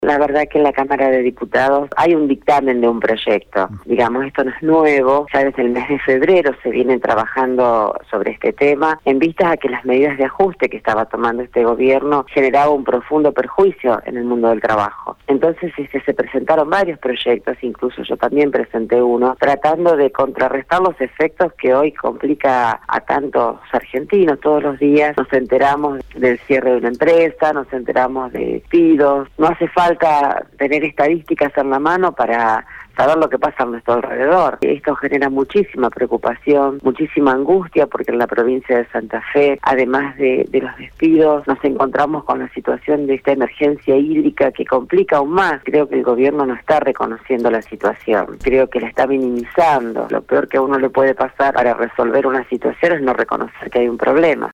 Así lo indicó la diputada nacional por el Frente para la Victoria, Silvina Frana, en diálogo con radio EME. La legisladora se refirió a la demora en el tratamiento de la ley antidespidos.